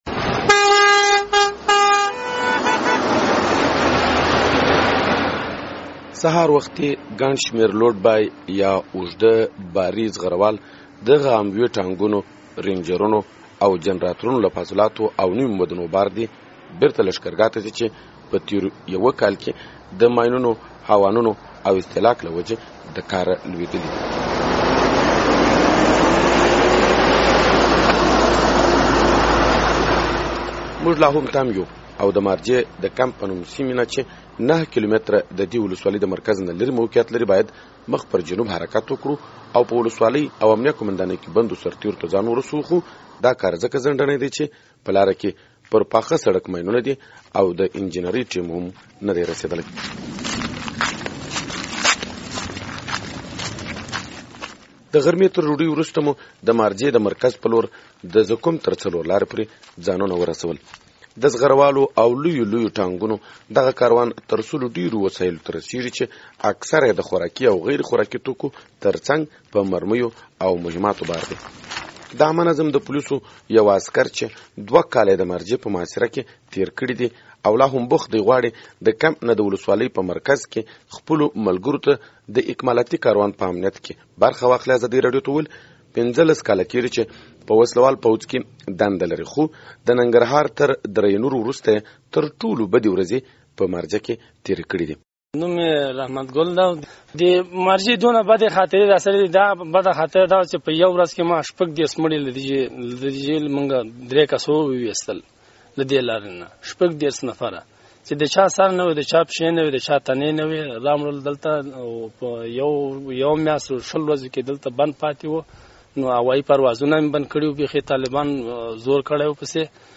هغه د خپل سفر په درېیمه ورځ د مارجې وروستیو حالاتو ته کتنه کړې ده او د سیمې انځور وړاندې کوي: